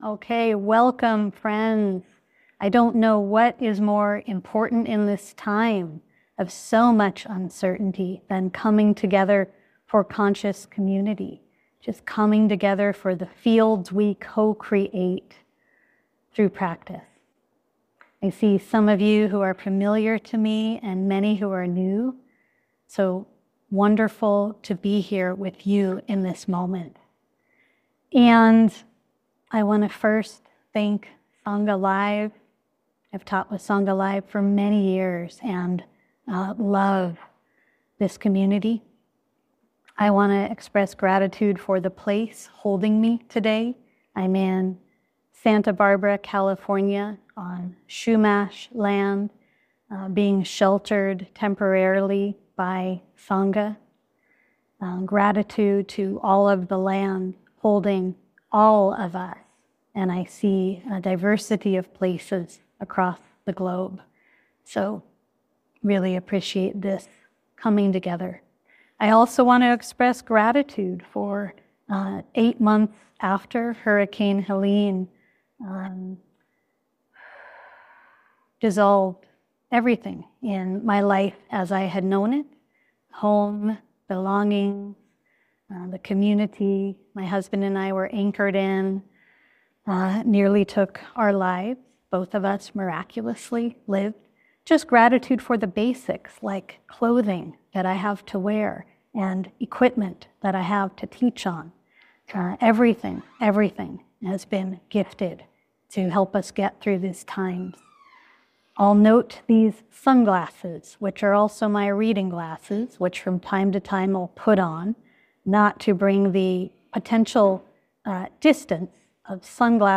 To find ground in the midst of accelerated change is our practice. In this Sunday insight gathering we will explore how to fortify our embodiment of emotional resiliency, seeing beyond fear, conscious grief, and widening perspective.